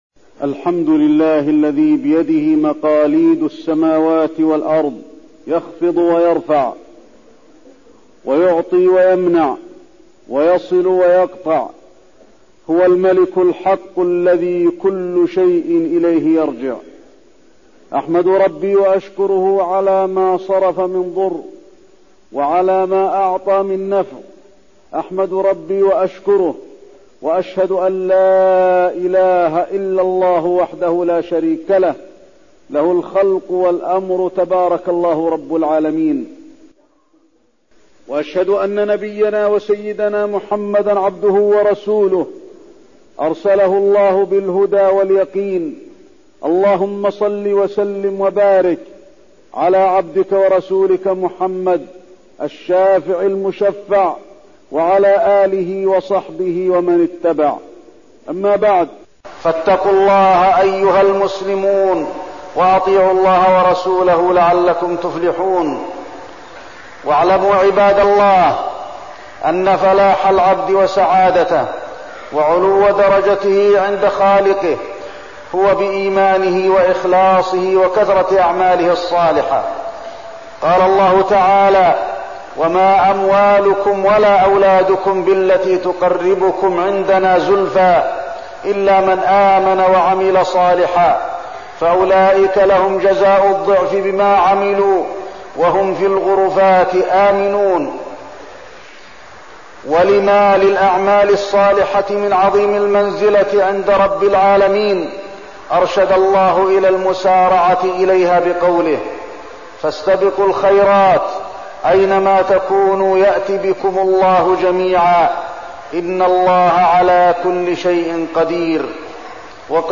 تاريخ النشر ١٤ صفر ١٤١٥ هـ المكان: المسجد النبوي الشيخ: فضيلة الشيخ د. علي بن عبدالرحمن الحذيفي فضيلة الشيخ د. علي بن عبدالرحمن الحذيفي الأعمال الصالحة